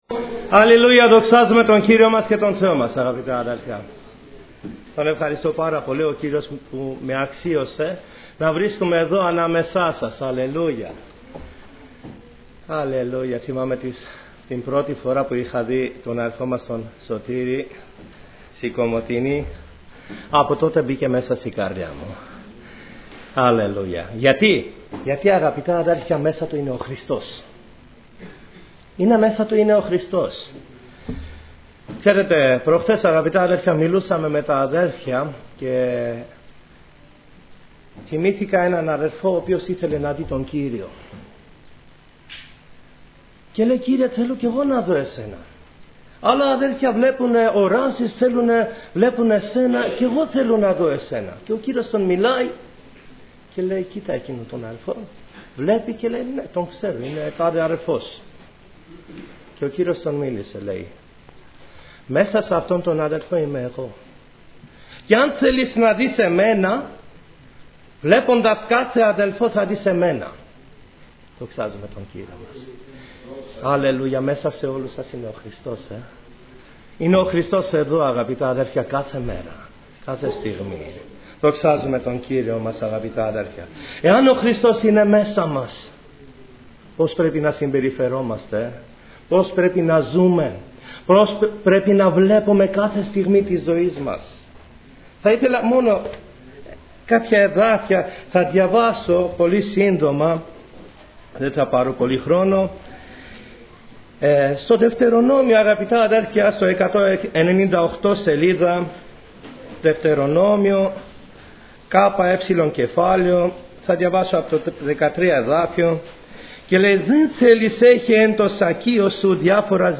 Διάφοροι αδελφοί
Ομιλητής: Διάφοροι Ομιλητές
Σειρά: Κηρύγματα